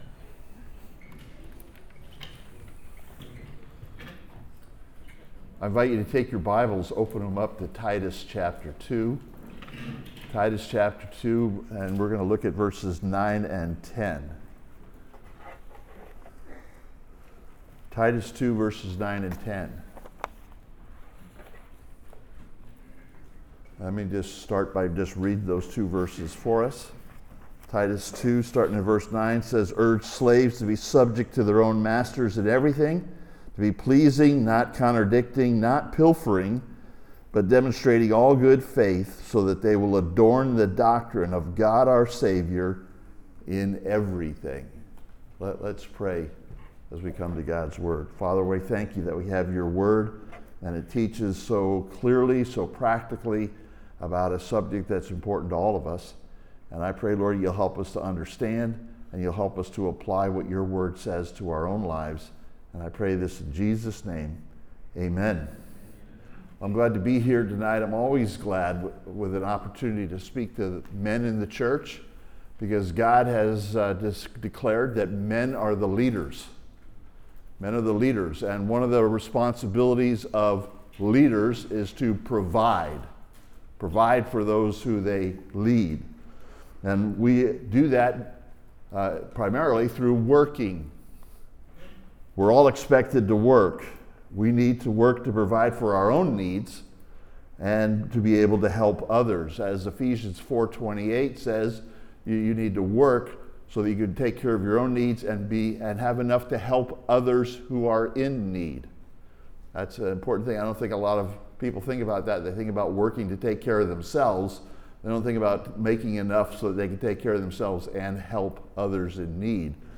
Men At Work (Sermon) - Compass Bible Church Long Beach